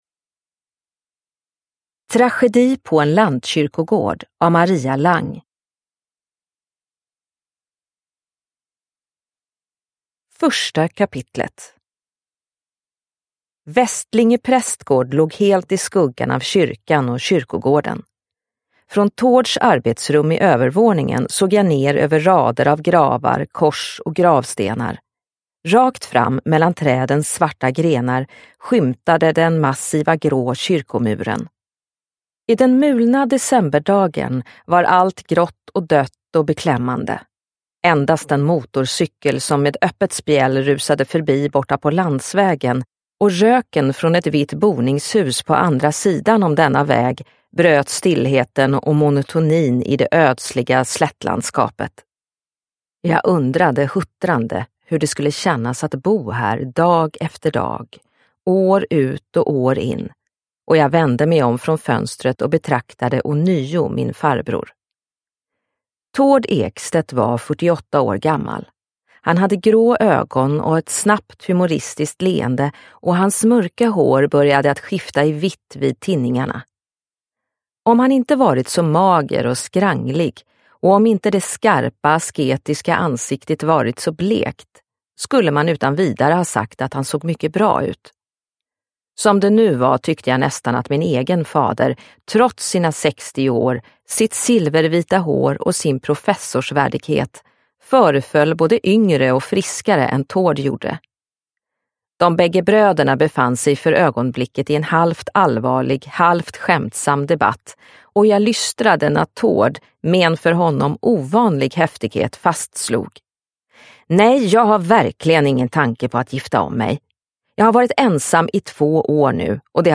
Tragedi på en lantkyrkogård – Ljudbok – Laddas ner